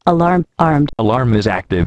bulletUser selectable voice, male or female.
Want to here how the module sounds?
protalk_sample.wav